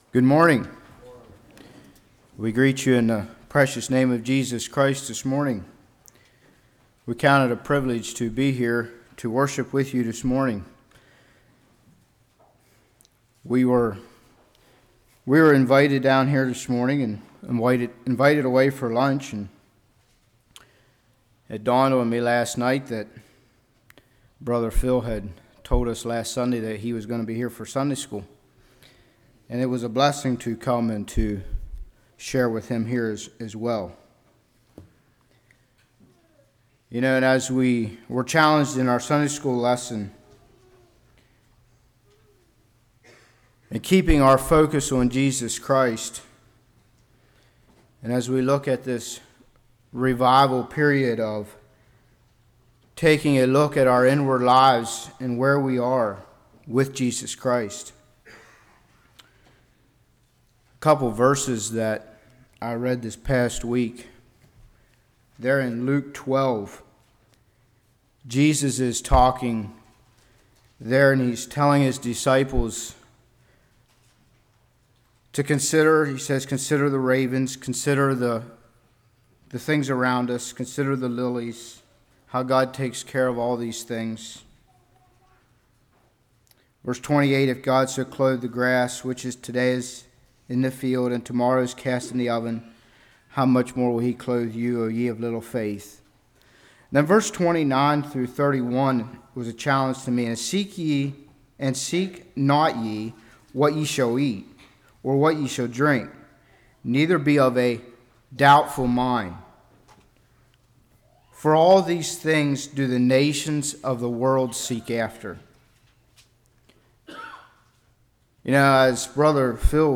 Spring Revival 2014 Passage: 1 Thessalonians 5:1-8 Service Type: Revival Personal Holiness Holiness in Home Holiness in Church « Walking in the Spirit What Are You Neglecting?